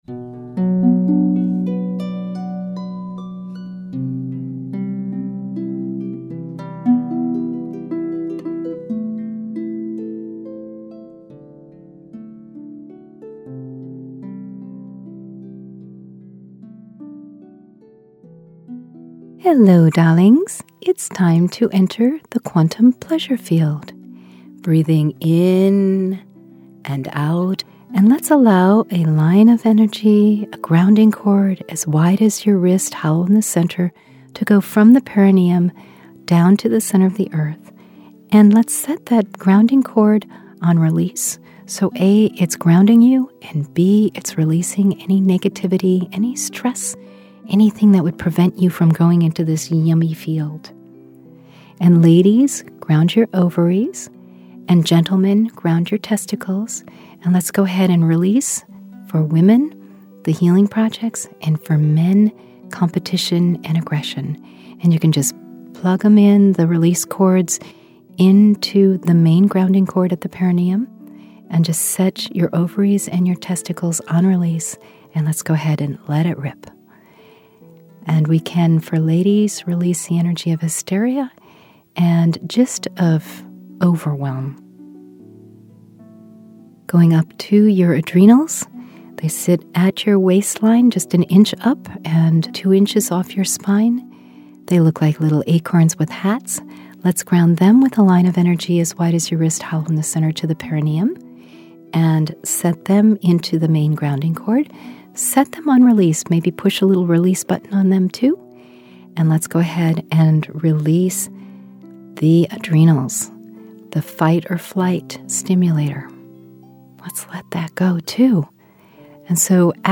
When you’re needing a fast and sweet entry into the Quantum Pleasure Field, you’ll want to practice this guided meditation. Release everything that blocks you from enjoying the pleasure field and become revitalized in your waking hours.